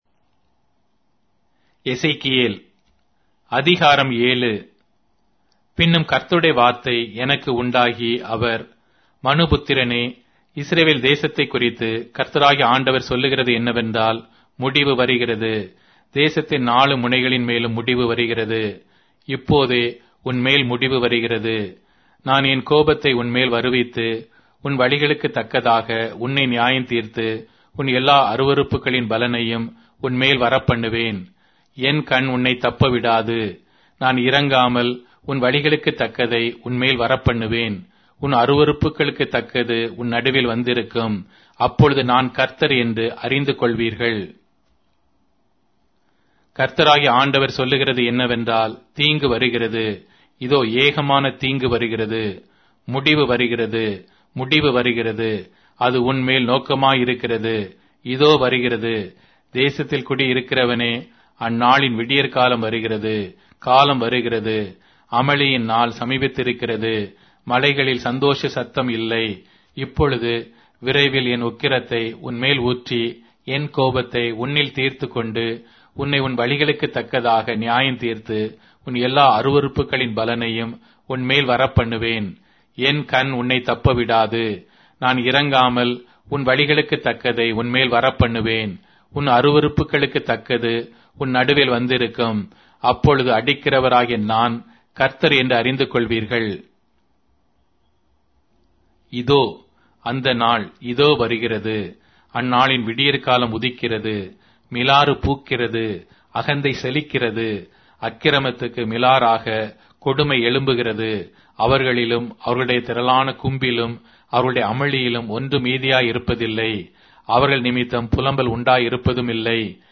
Tamil Audio Bible - Ezekiel 9 in Ocvkn bible version